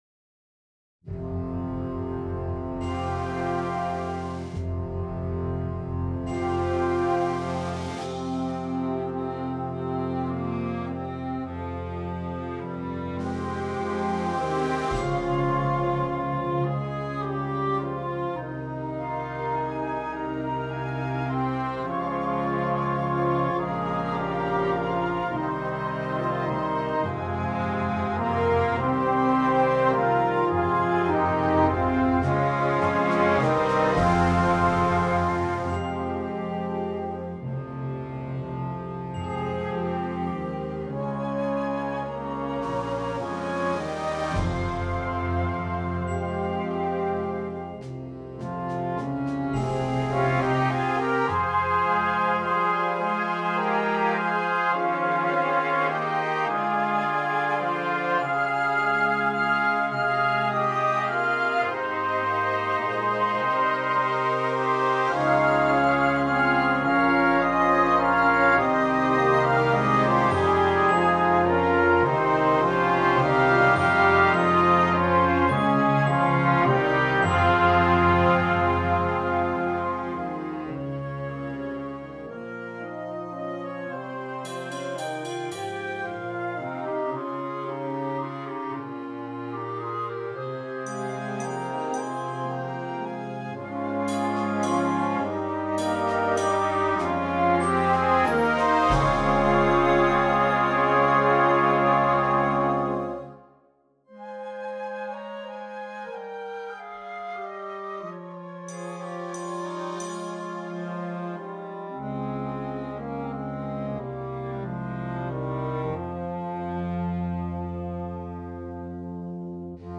Concert Band
Canadian Folk Song
hauntingly beautiful folk song